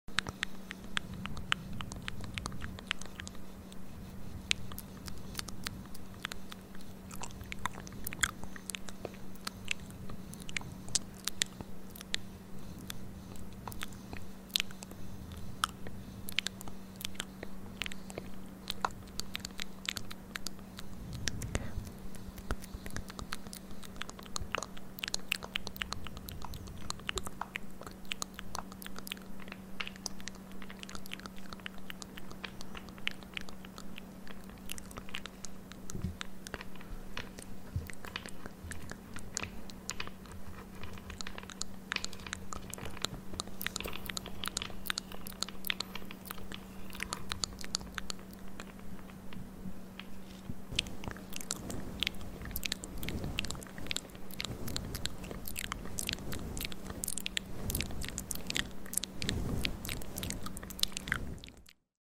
ASMR ✿ Massage on Your sound effects free download